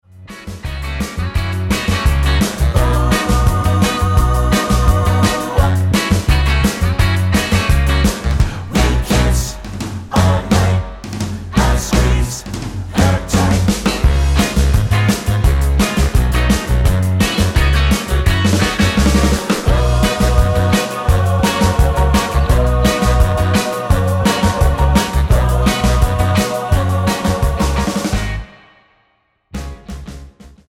Tonart:F# mit Chor
Die besten Playbacks Instrumentals und Karaoke Versionen .